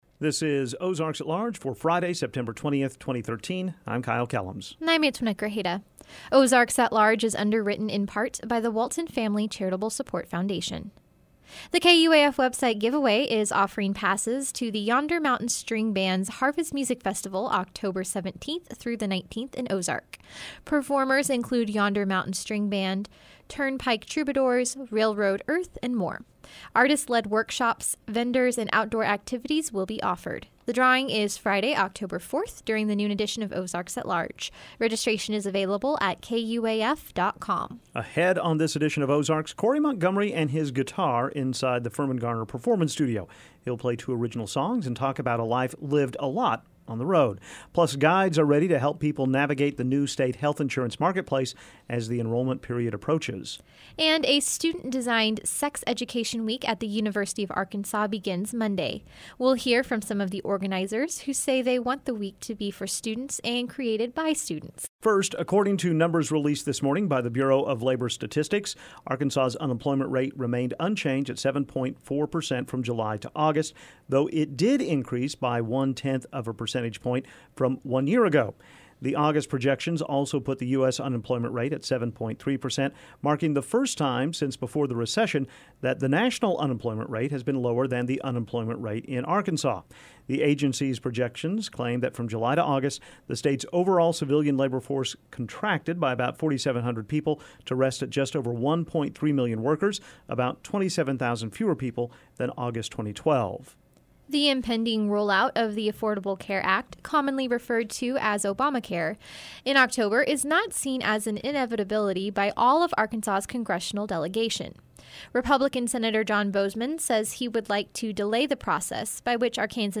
Plus guides are getting ready to help people navigate the new state health insurance marketplace as the enrollment period approaches. And a student-designed sex education week at the University of Arkansas begins Monday. We'll hear from some of the organizers who say they want the week to be for students and created by students..